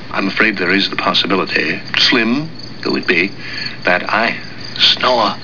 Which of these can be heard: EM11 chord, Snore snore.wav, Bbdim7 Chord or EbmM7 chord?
Snore snore.wav